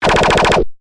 枪支和武器的声音效果 " SHOOT023
描述：拍摄声音
标签： 手枪 手枪 激光 武器 夹子 射击 音频
声道立体声